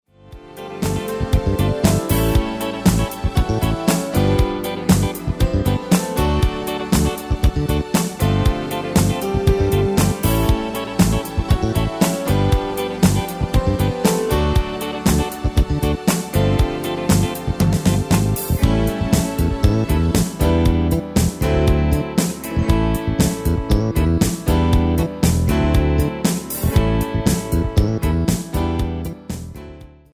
Demo/Koop midifile
Genre: Disco
Toonsoort: A
Demo's zijn eigen opnames van onze digitale arrangementen.